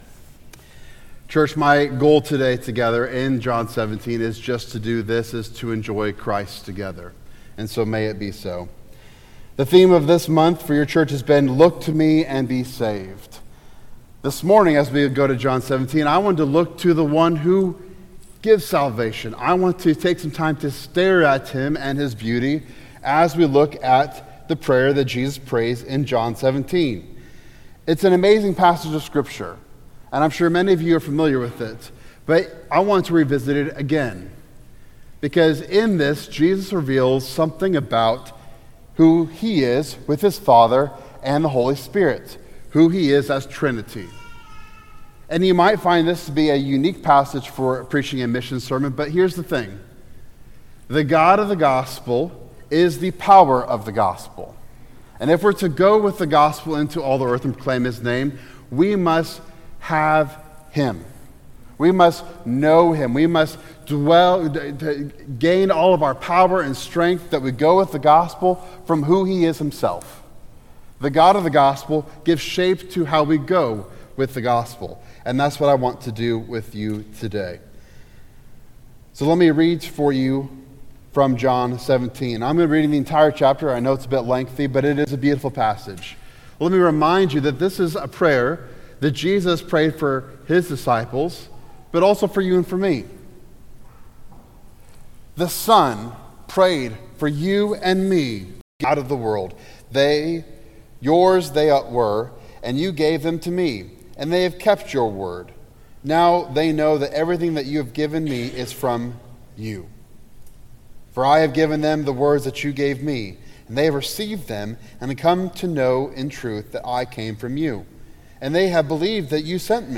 Missions and the Trinity - Missionary Guest Speaker